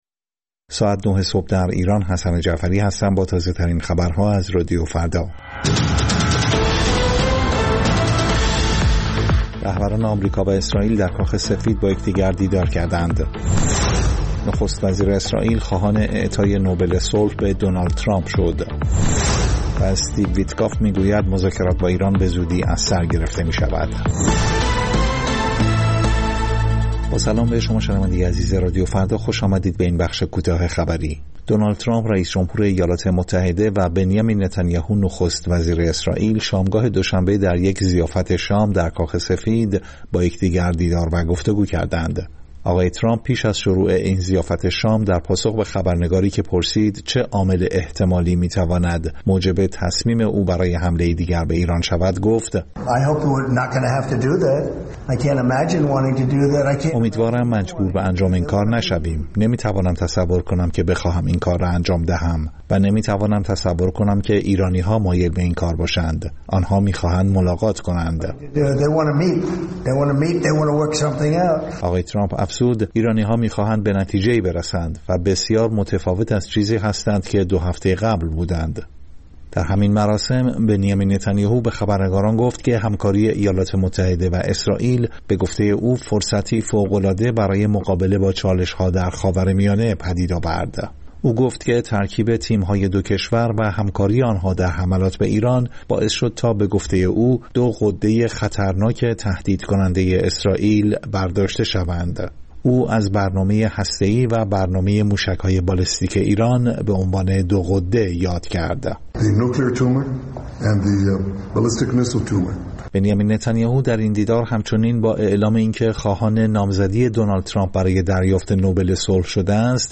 سرخط خبرها ۹:۰۰
پخش زنده - پخش رادیویی